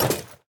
equip_netherite3.ogg